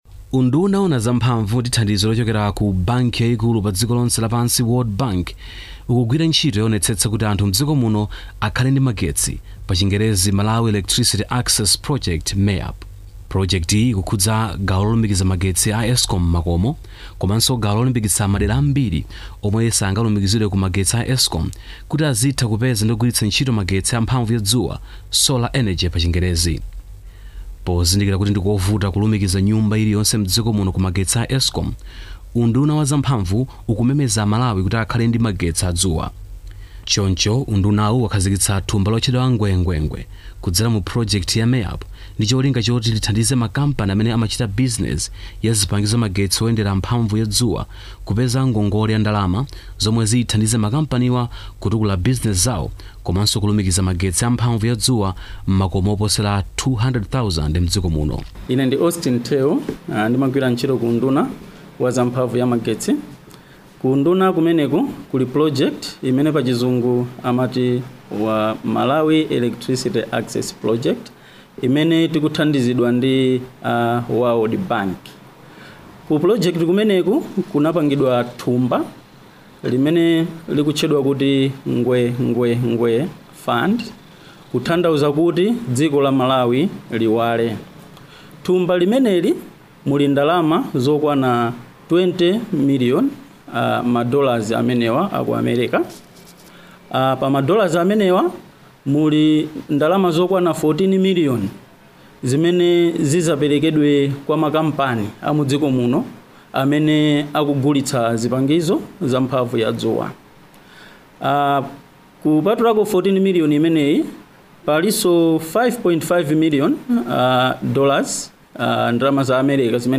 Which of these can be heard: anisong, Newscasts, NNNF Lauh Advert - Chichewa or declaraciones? NNNF Lauh Advert - Chichewa